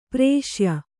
♪ prēṣya